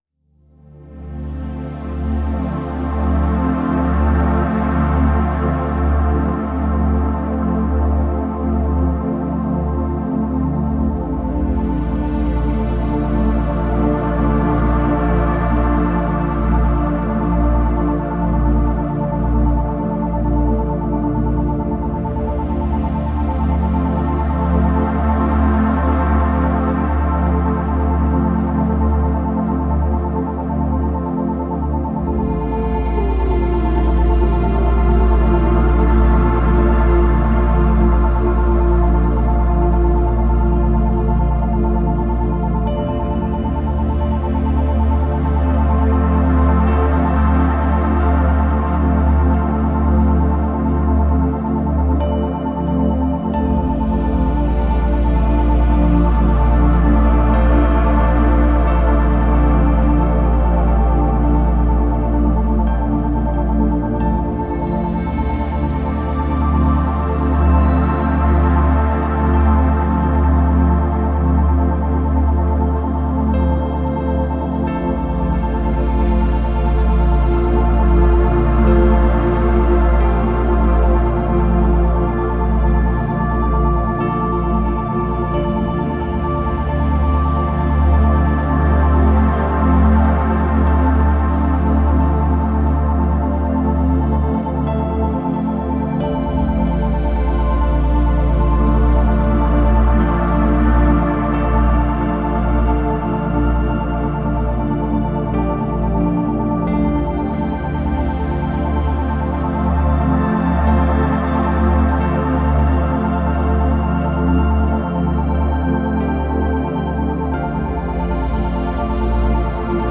La fréquence 888 Hz maximise la richesse matérielle
888-Hz-MaximIiser-labondance-et-richisses.mp3